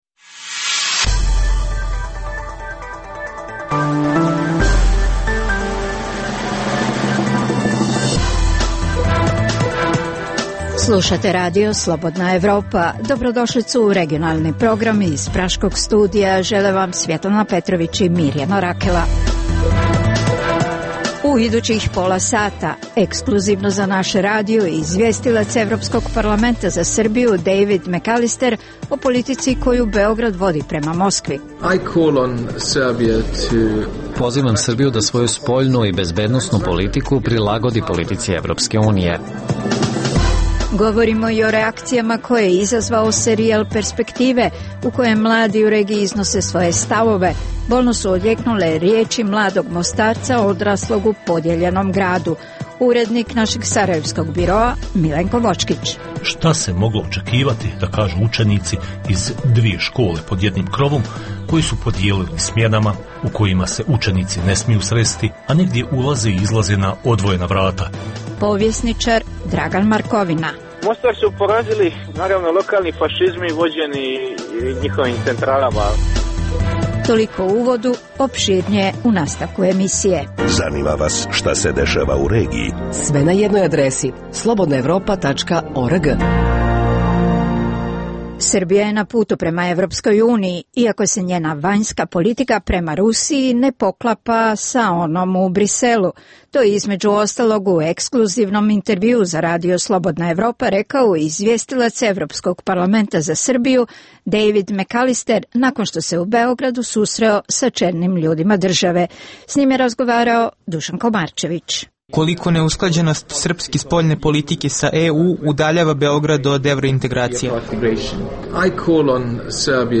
- Intervju sa izvjestiocem Evropskog parlamenta za Srbiju Dejvidom Mekalisterom.